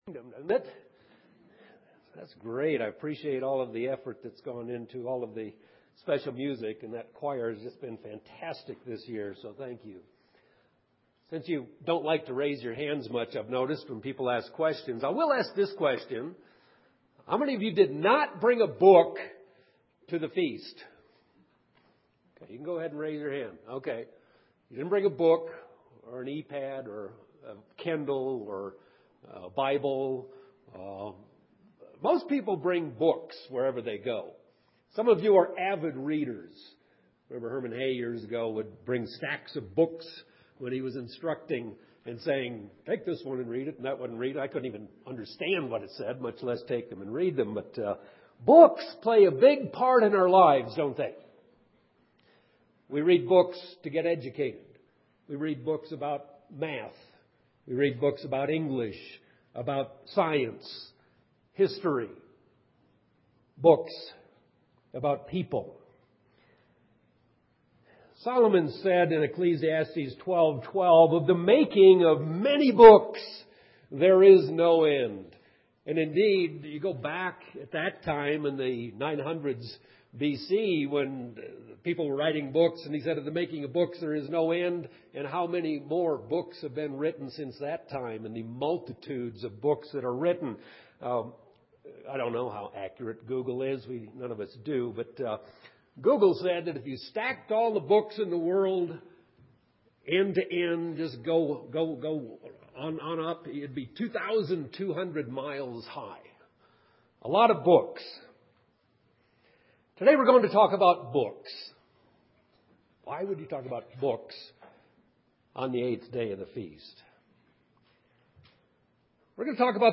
This sermon was given at the Steamboat Springs, Colorado 2012 Feast site.